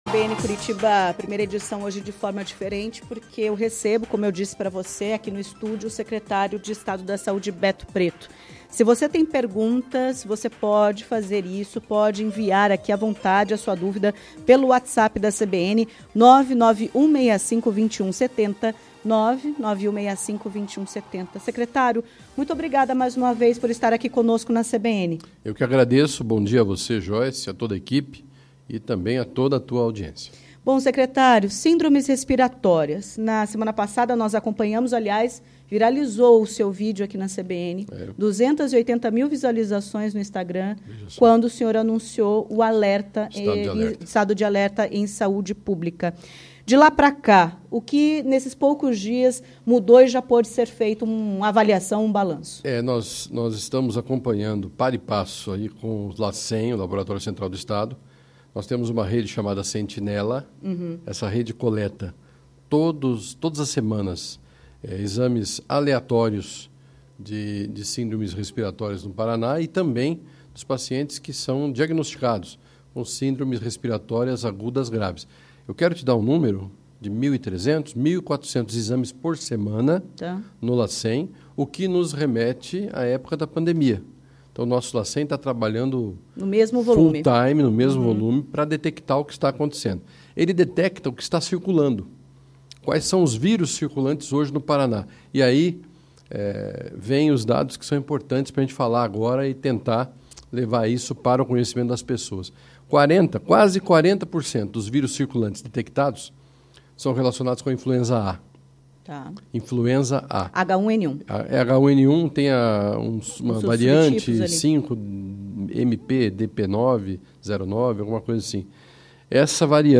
O alerta é do secretário de Estado da Saúde, Beto Preto, em entrevista à CBN Curitiba nesta terça-feira (10). De acordo com ele, a pasta já solicitou mais doses de Tamiflu para o Ministério da Saúde e deve ainda fazer aquisição separadamente.